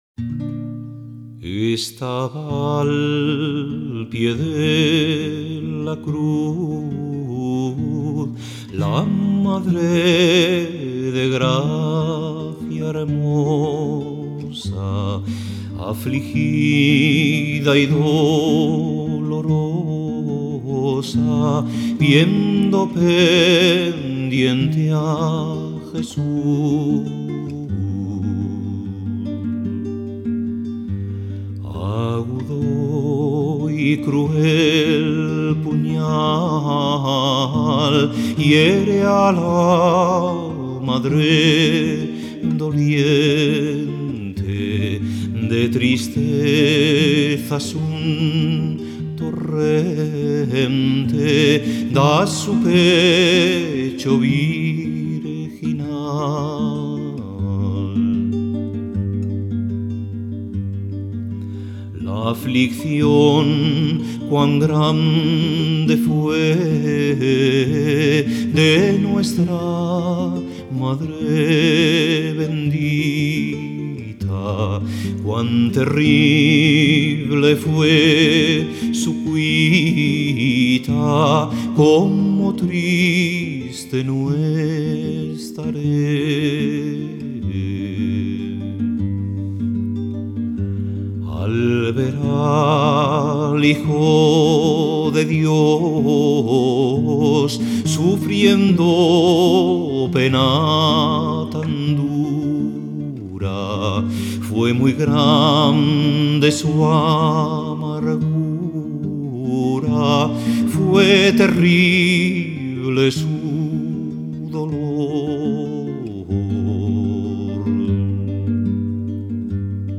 Voz y guitarra